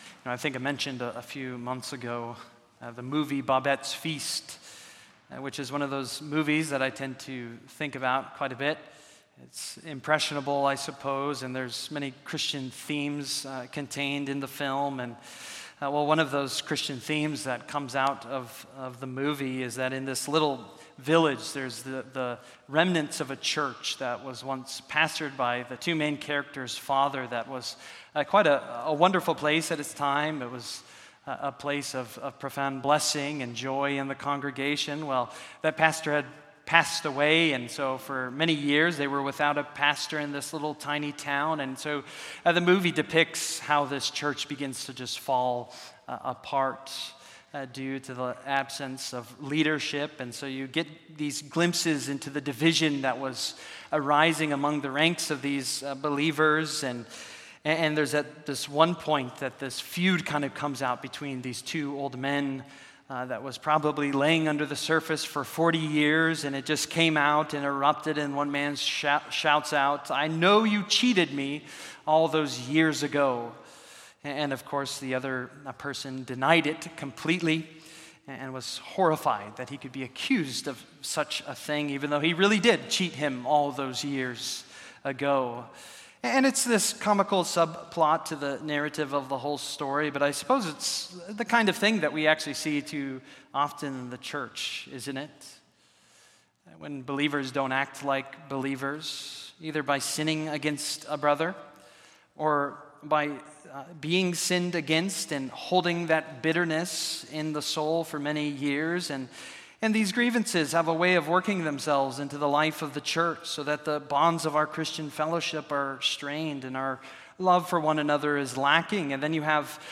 Service: Sunday Evening